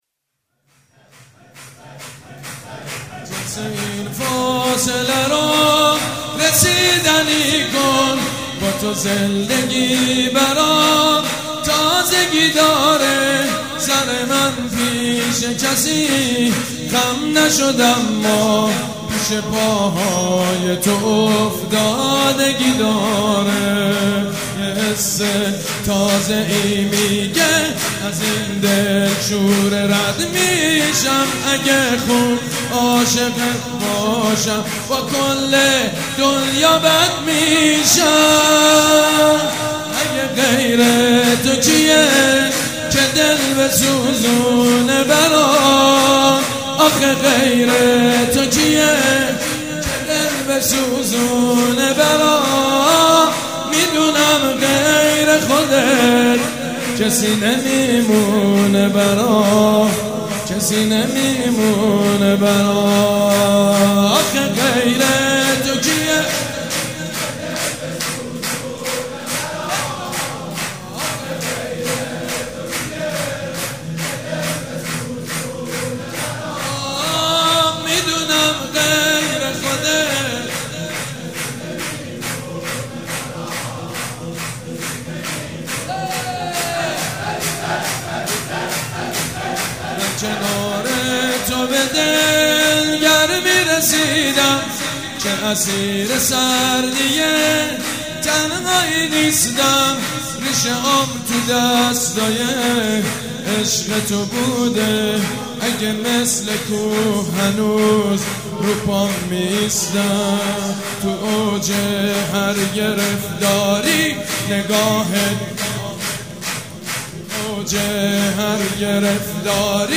سرود: جنس این فاصله رو رسیدنی کن